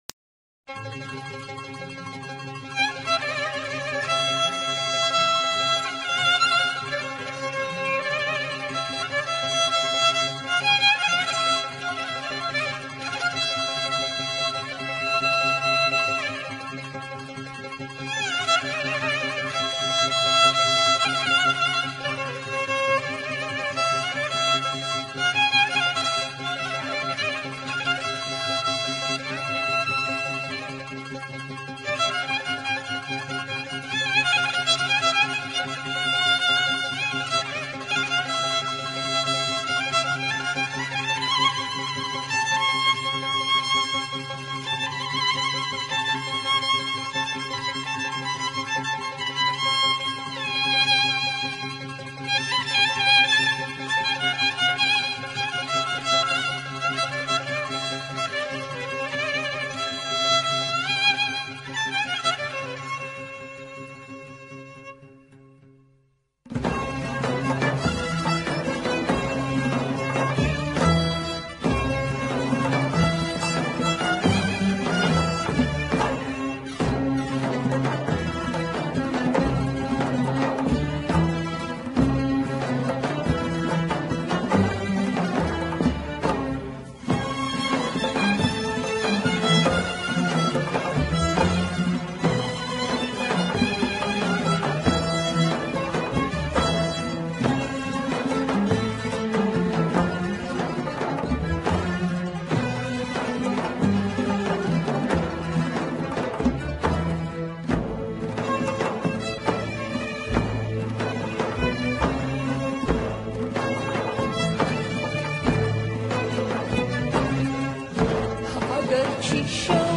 آهنگ کردی فولکلور